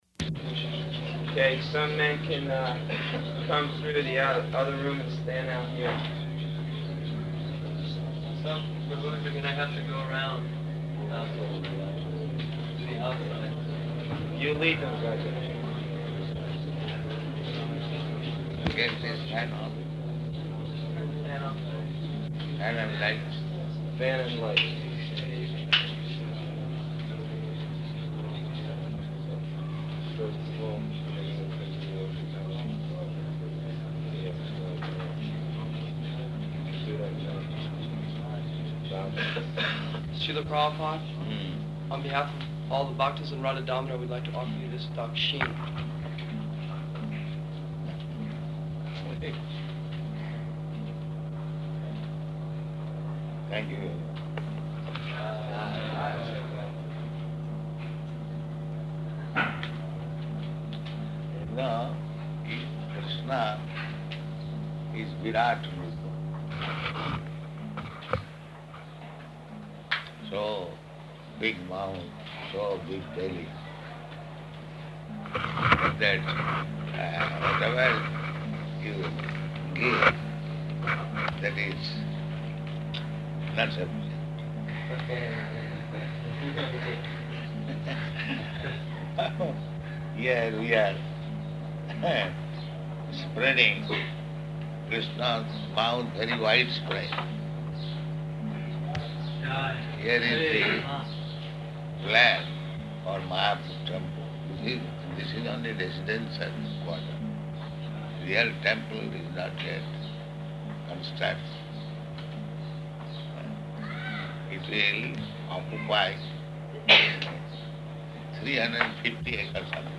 Room Conversation With Rādhā-Dāmodara Saṅkīrtana Party
-- Type: Conversation Dated: March 16th 1976 Location: Māyāpur Audio file